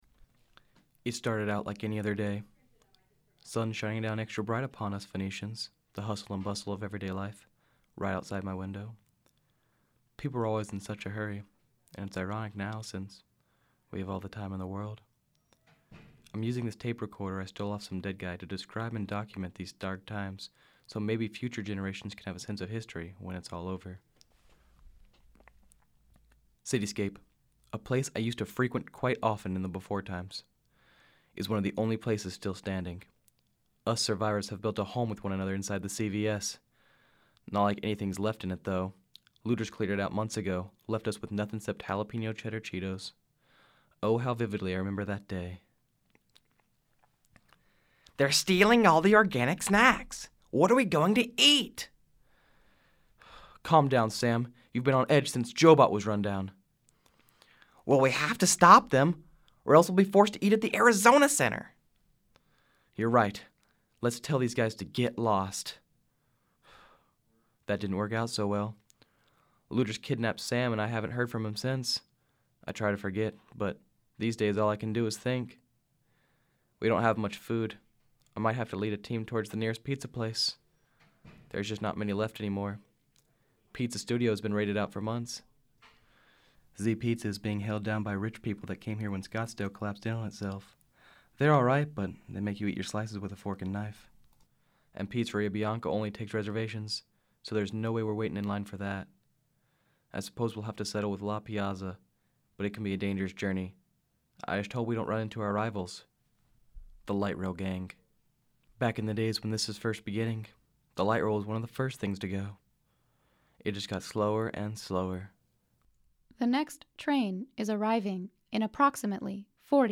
Audio: Found recorder contains tape of man’s bold and valiant quest for pizza, his last
A leader of a small group of survivors used the recorder to document his quest to find pizza.